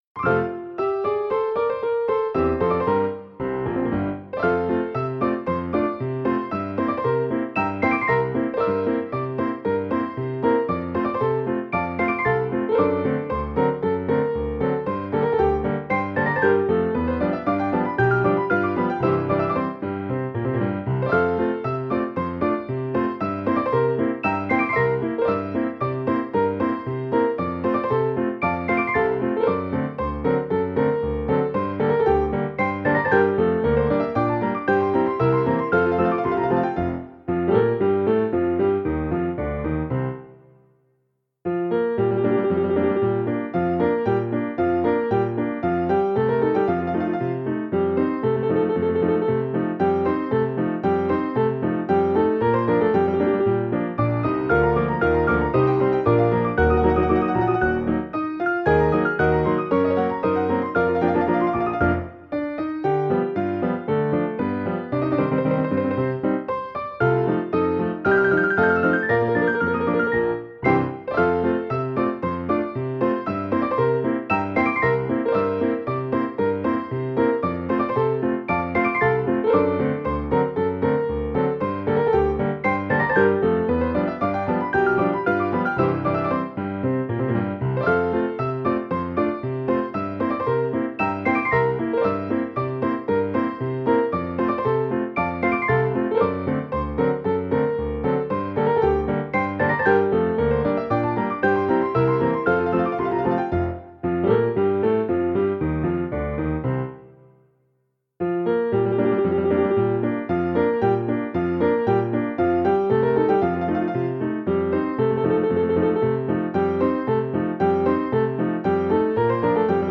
rollo de pianola